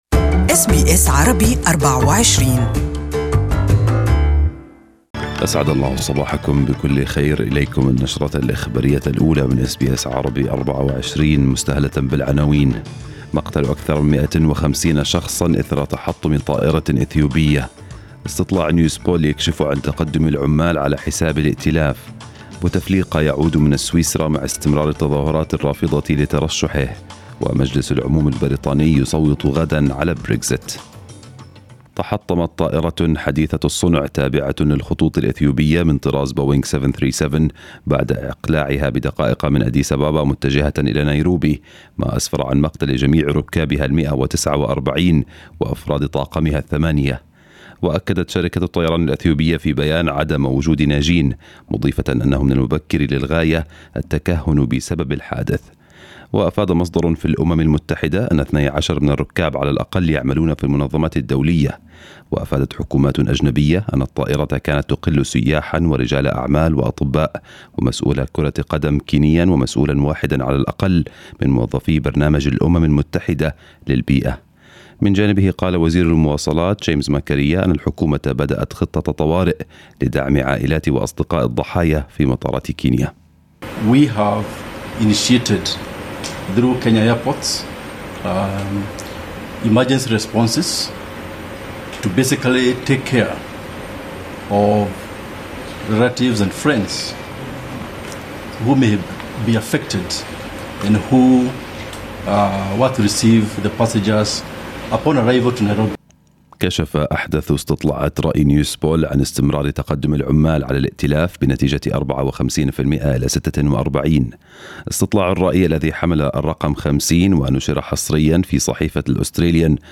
News Bulletin in Arabic for the morning